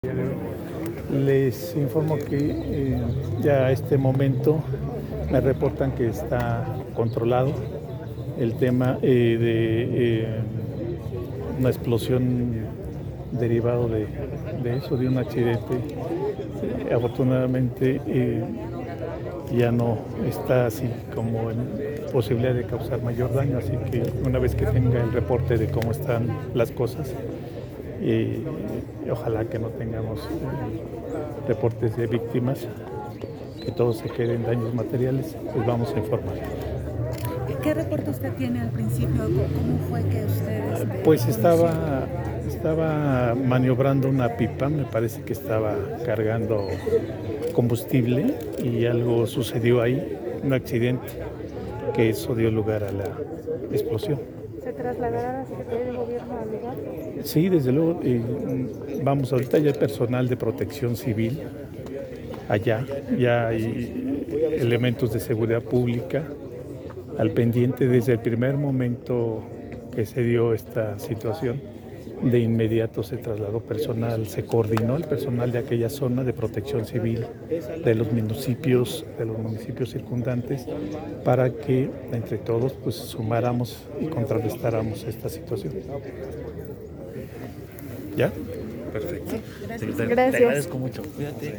EXPLOSIÓN EN GASOLINERA EN TULA DE ALLENDE JACALA MULTIMEDIOS Declaraciones Secretario de Gob- Declaraciones del Secretario de Gobierno Guillermo Olivares Reya Fuerte explosión se registra en una gasolinera ubicada en ls colonia Iturbide en Tula de Allende.